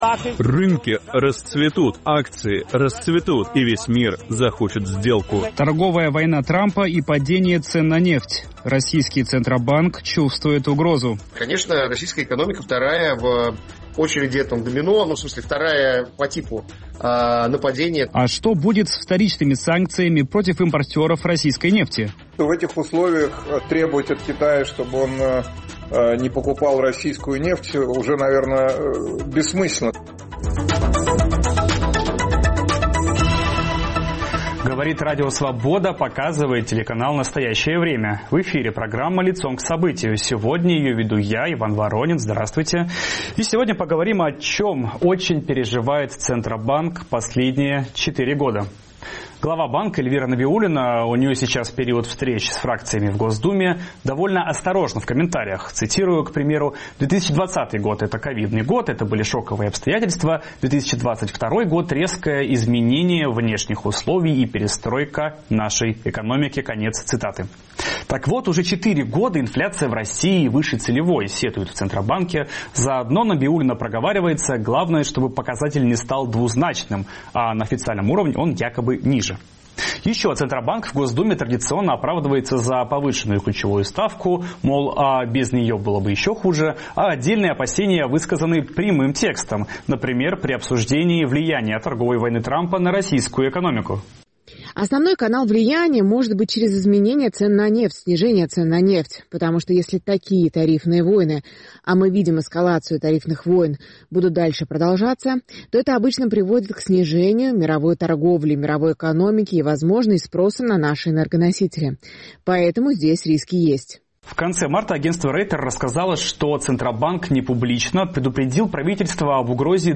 говорим с экономистом